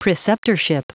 Prononciation du mot preceptorship en anglais (fichier audio)
Prononciation du mot : preceptorship